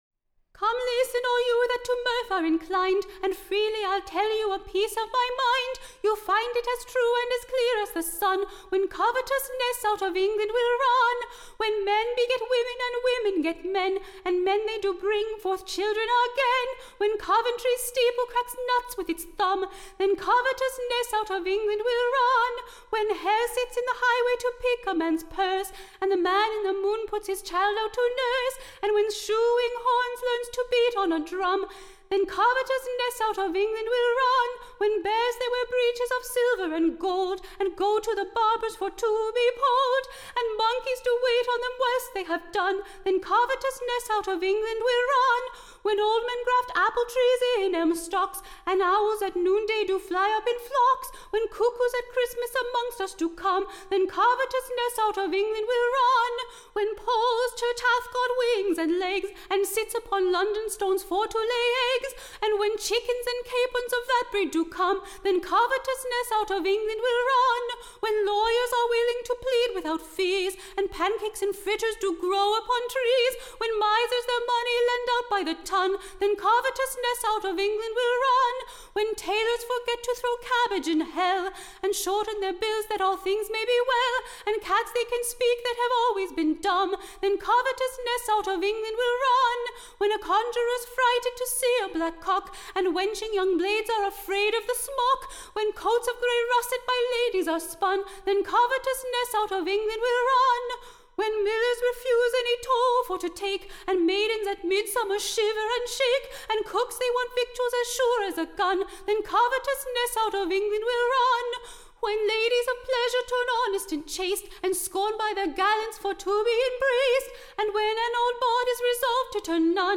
Recording Information Ballad Title The Plow-mans Prophesie: / OR, / The Country-mans Calculation.